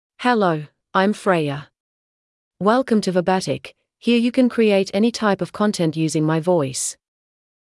Freya — Female English (Australia) AI Voice | TTS, Voice Cloning & Video | Verbatik AI
Freya is a female AI voice for English (Australia).
Voice sample
Listen to Freya's female English voice.
Freya delivers clear pronunciation with authentic Australia English intonation, making your content sound professionally produced.